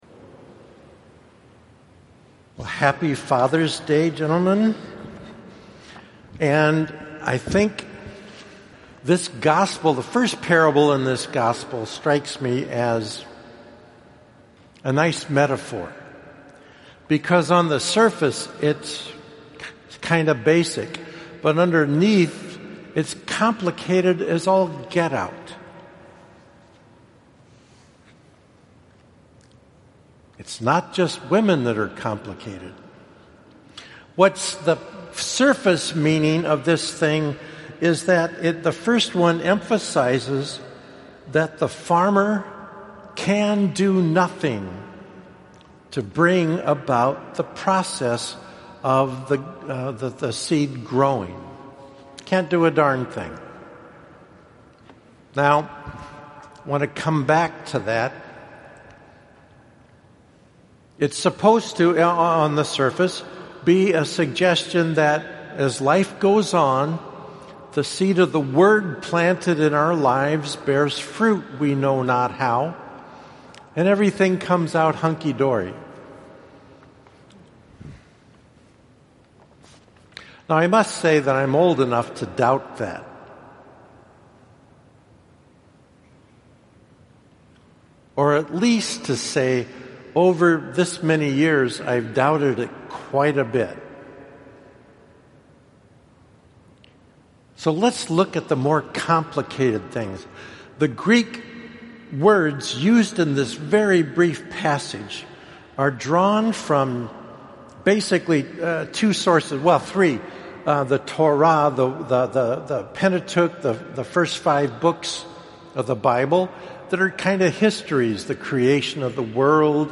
11th Sunday Ordinary Time – Audio Recap
Whatever the case, I believe this homily had some good stuff in it.